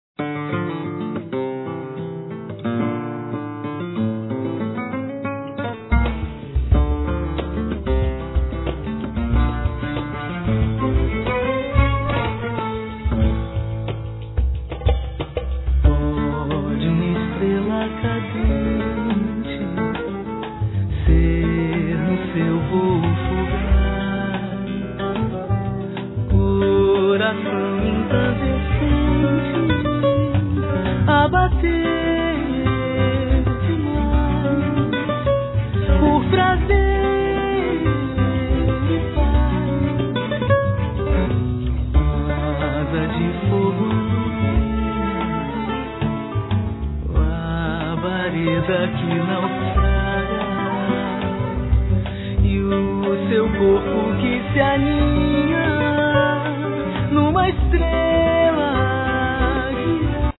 Guitar
Bandolim